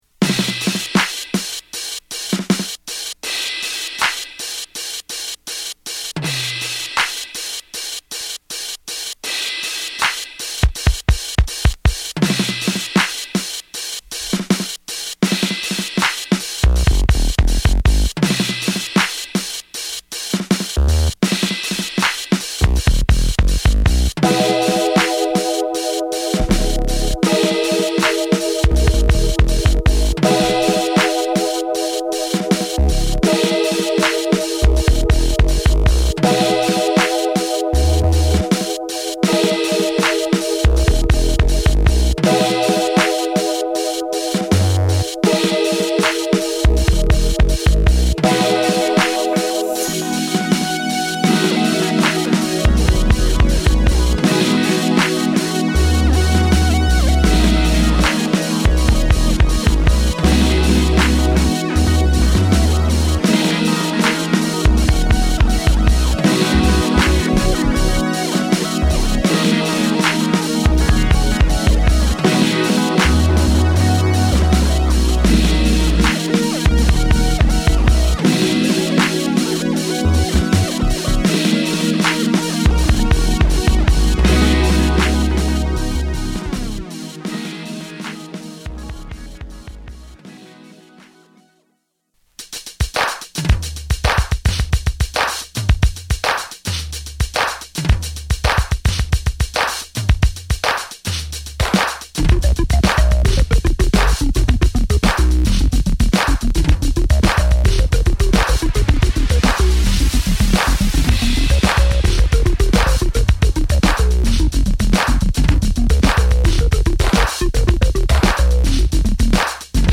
期待を裏切らないエレクトロ〜ファンク・ビーツを満載！